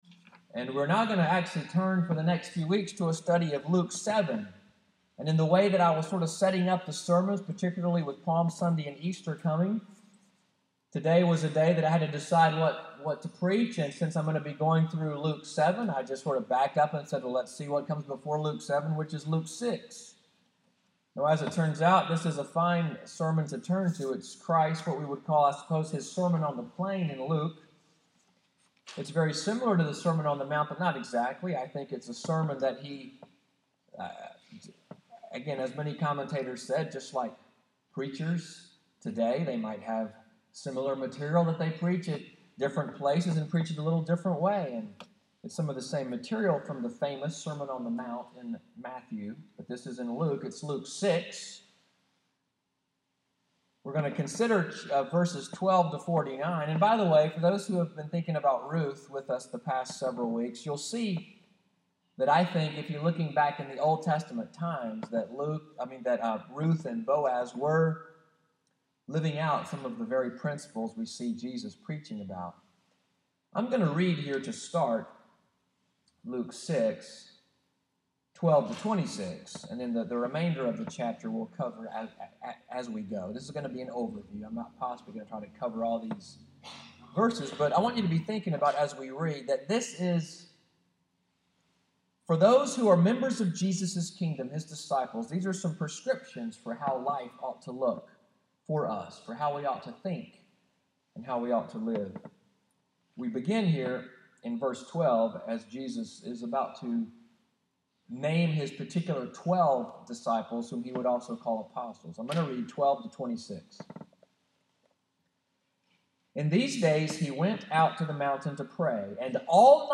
MORNING WORSHIP service at NCPC (PCA), “Kingdom Values,” March 13, 2016.